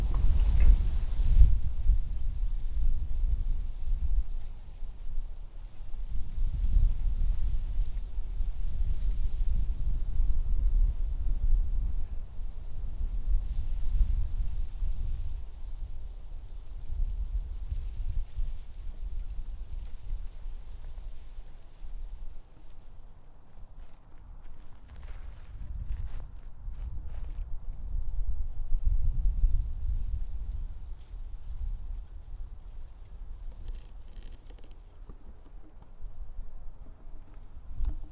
树叶沙沙作响
描述：留下在树的叶子
Tag: 树沙沙作响 树上 沙沙作响 树叶 树叶 沙沙